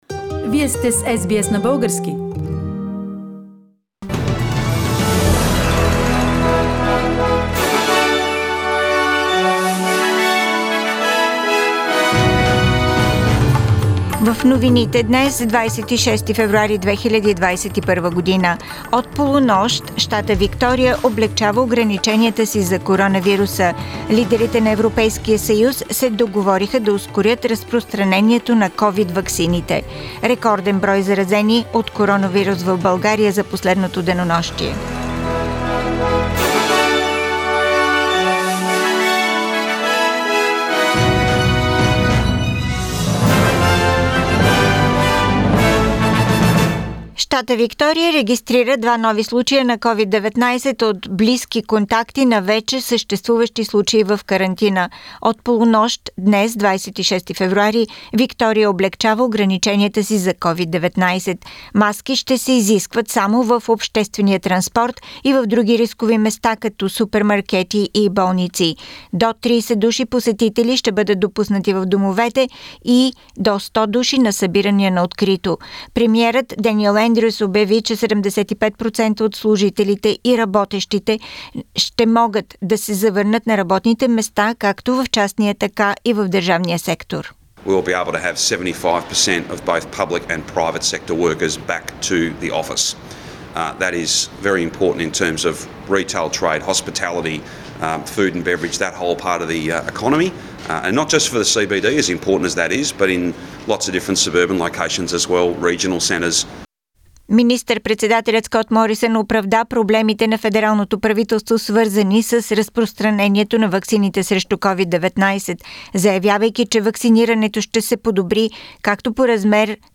Weekly Bulgarian News – 26th February 2021